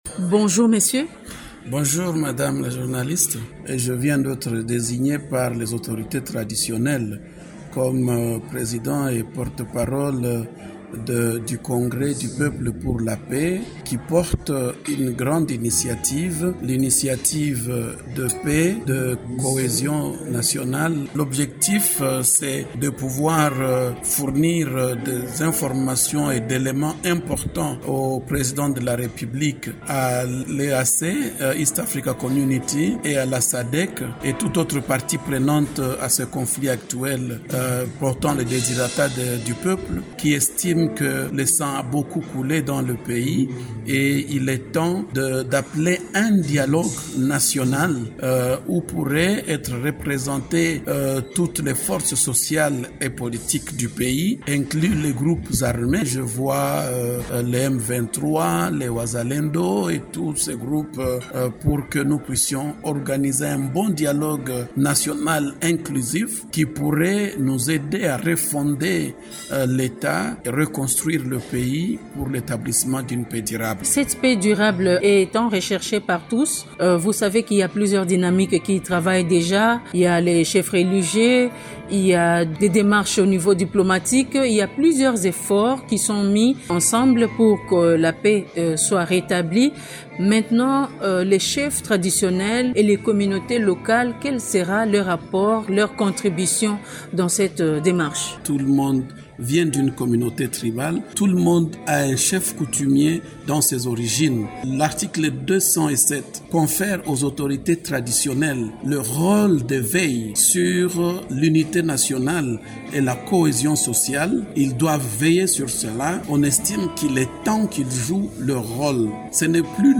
répond aux questions de